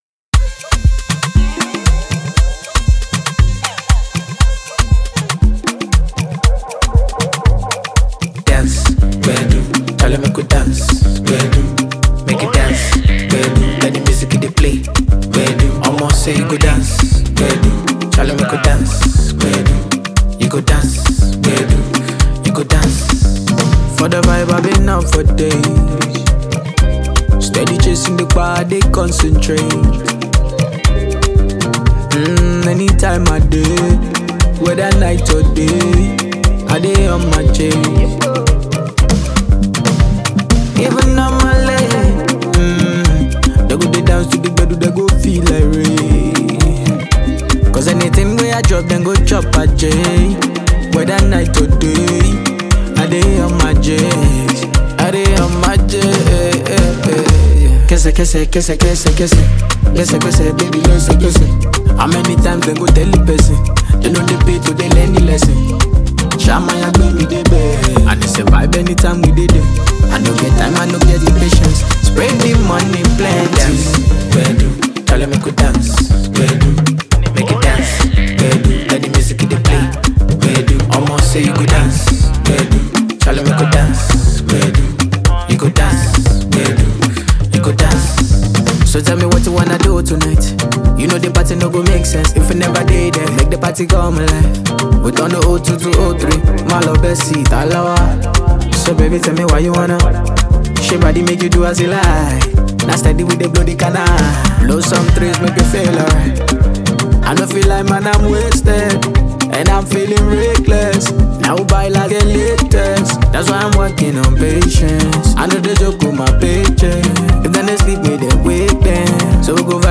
Renowned Nigerian Afrobeats talent and performer
The music scene is excited to embrace this energetic release